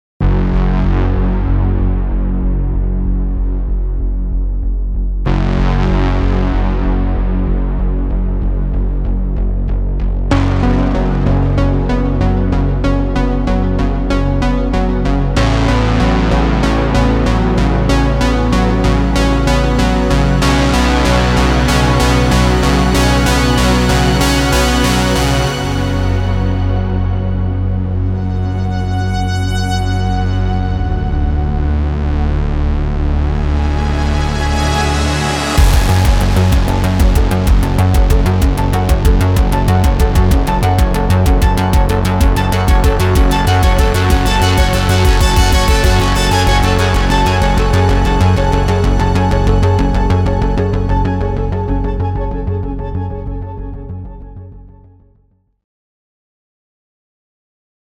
Audio demos
Organic, authentic, analog sound